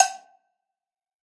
MANNIE_FRESH_cowbell_second_line_hi.wav